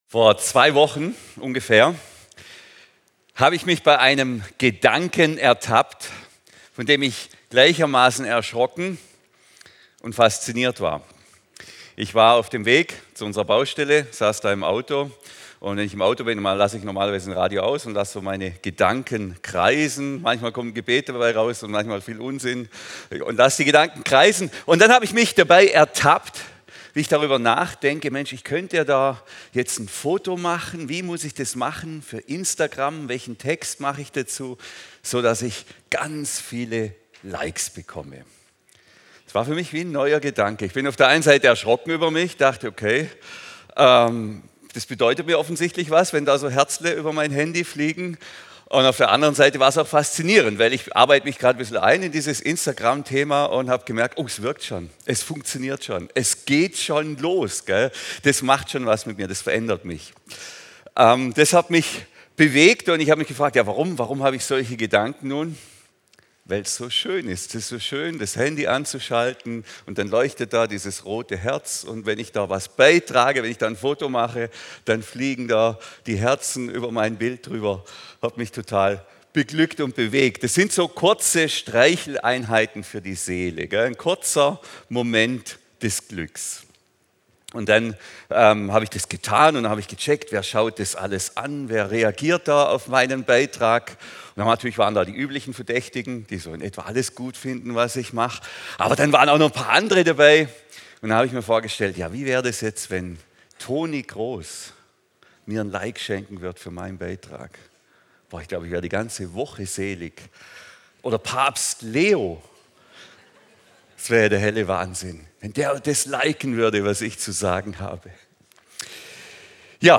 Kirche Lindenwiese - Predigten – Podcast